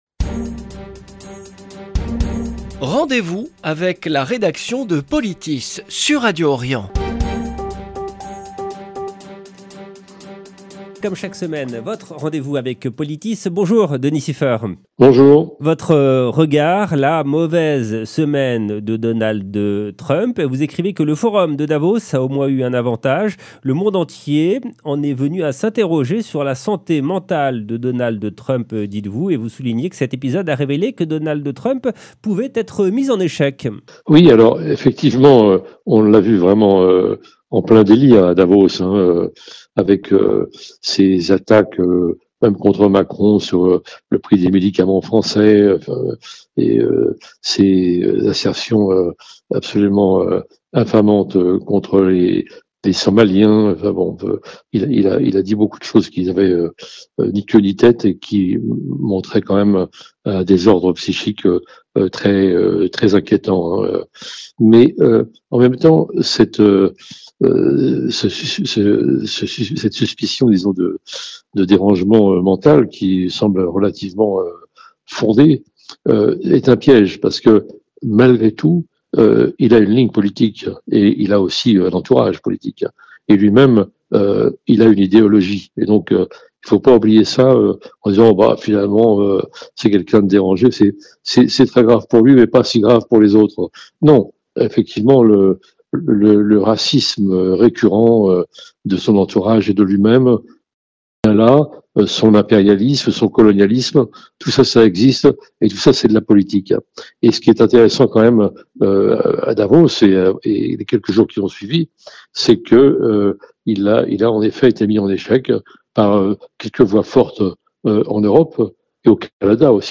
Rendez-vous avec la rédaction de POLITIS